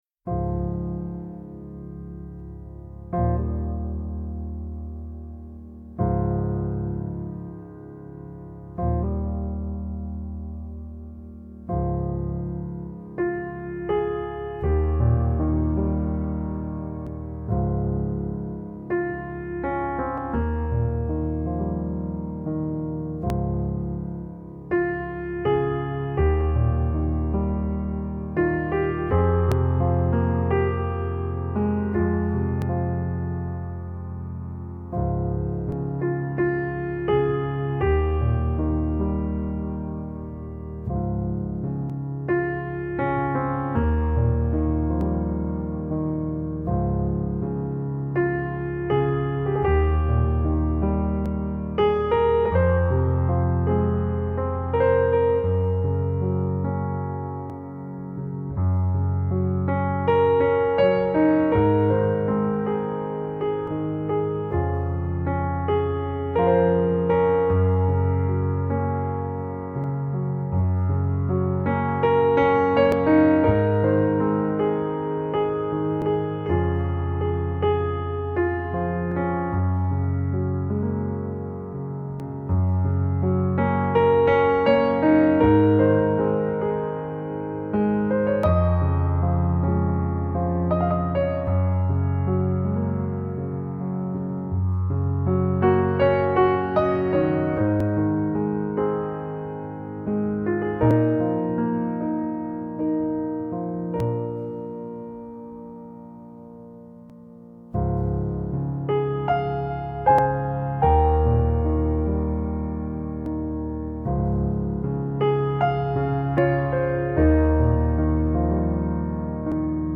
سبک آرامش بخش , پیانو , عصر جدید , موسیقی بی کلام
پیانو آرامبخش موسیقی بی کلام نیو ایج